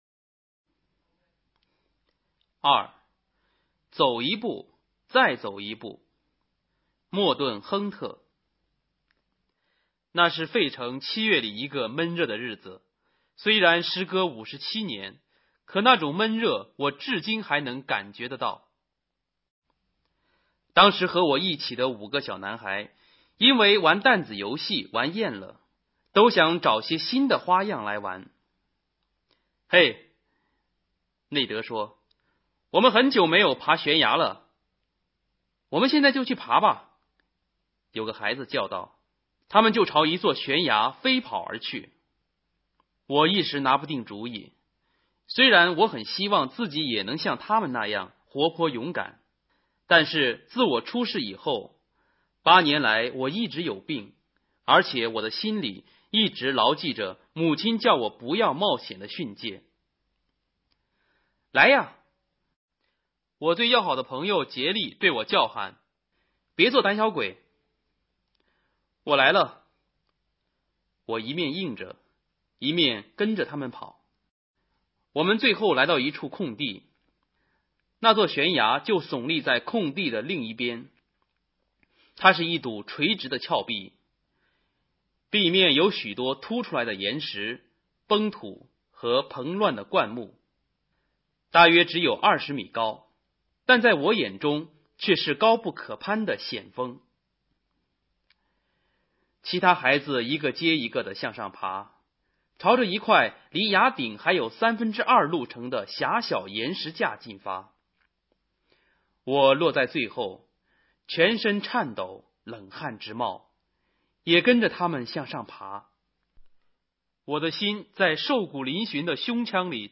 《走一步，再走一步》影音资料(男声朗读)1